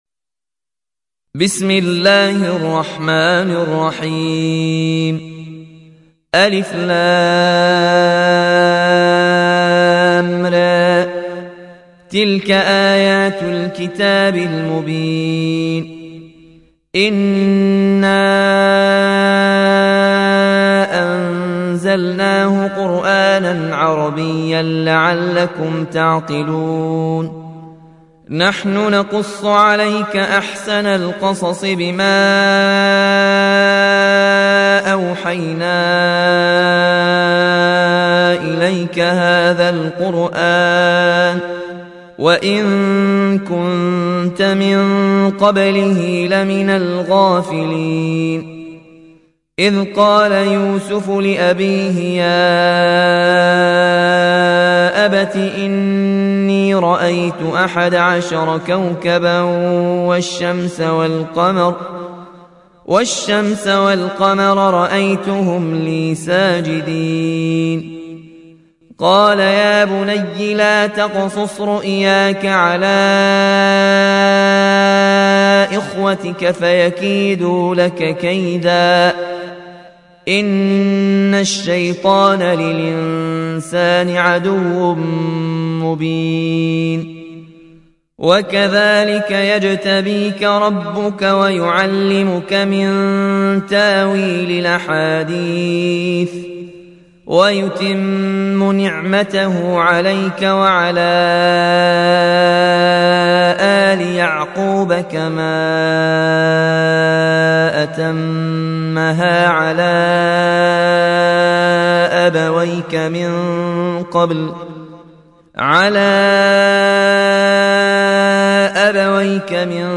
(روایت ورش)